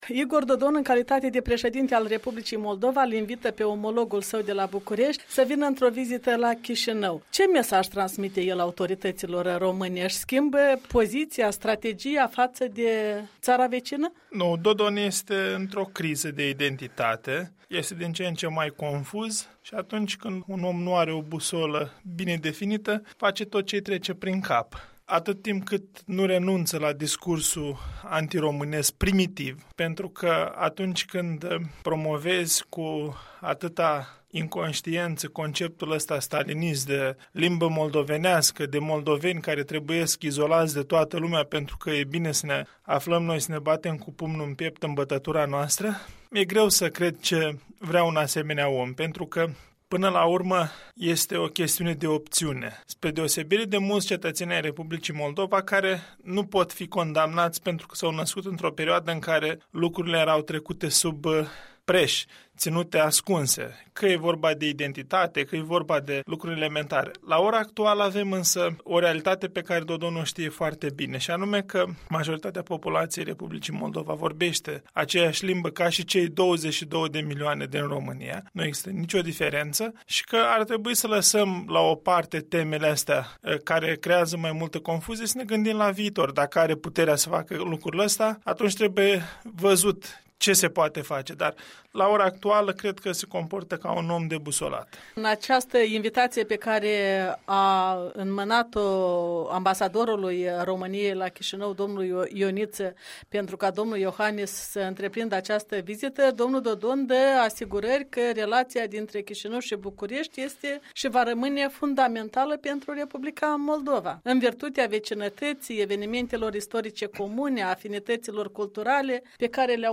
Un interviu cu liderul Partidului Mișcarea Populară din România.